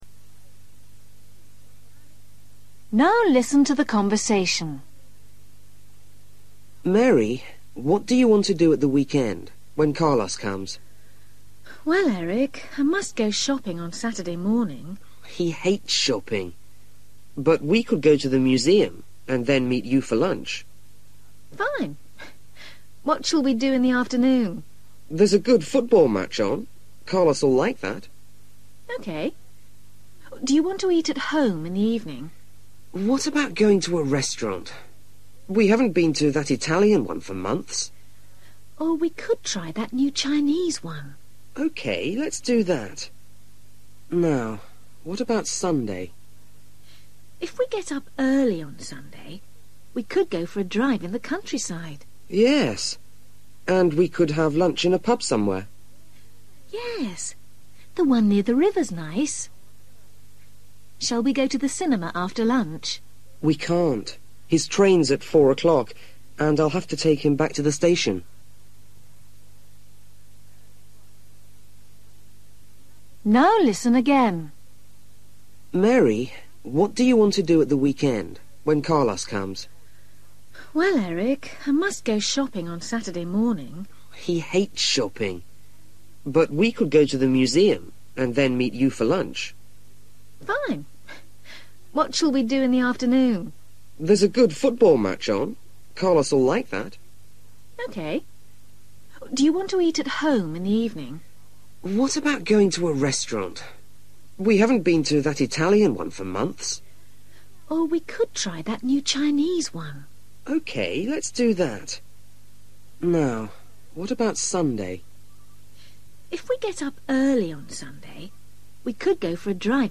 You will hear the conversation twice.